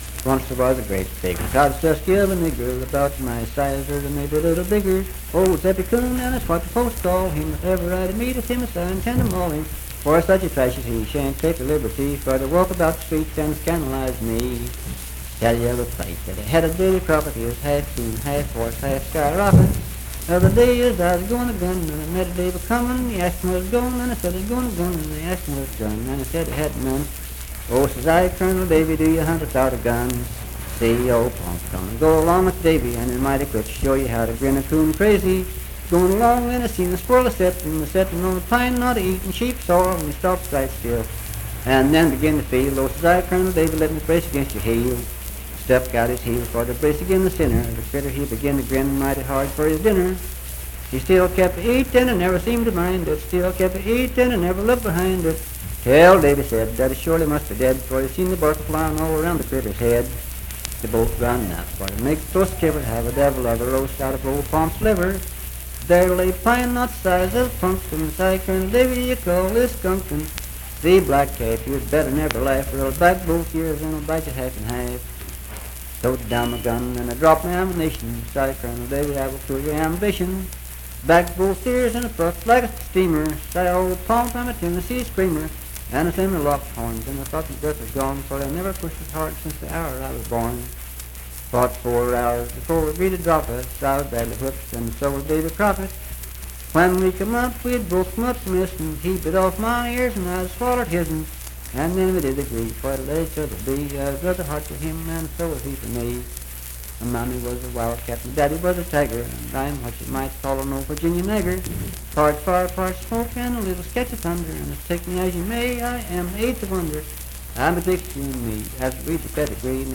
Unaccompanied vocal music performance
Verse-refrain 9(6).
Minstrel, Blackface, and African-American Songs
Voice (sung)